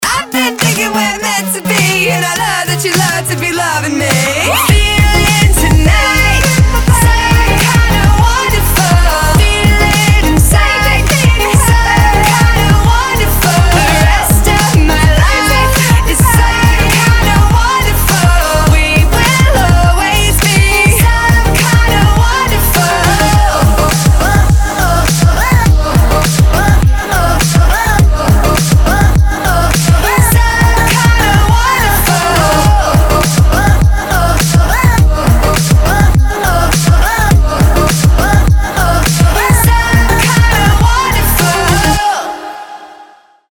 • Качество: 256, Stereo
поп
позитивные
громкие
женский вокал
веселые
dance
Electropop
vocal